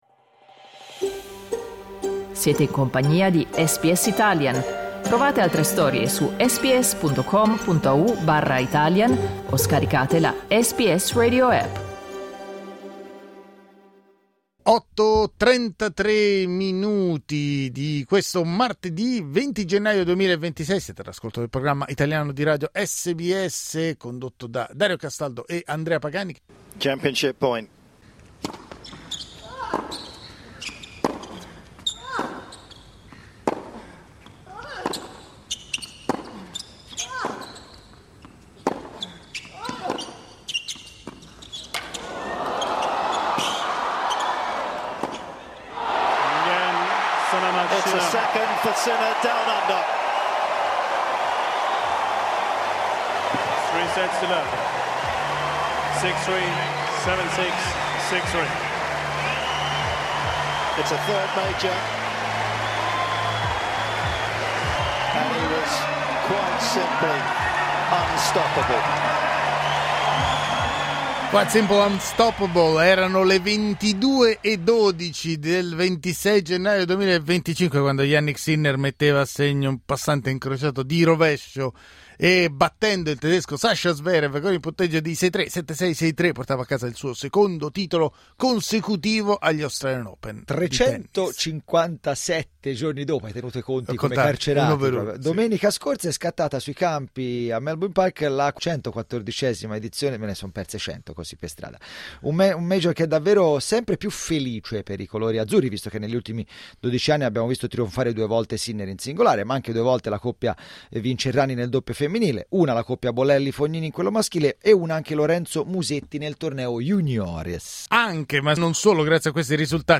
Ma cosa rappresenta oggi l'Australian Open per la nostra comunità? Lo abbiamo chiesto agli ascoltatori di SBS Italian.
Clicca sul tasto "play" per ascoltare il nostro dibattito sugli AO26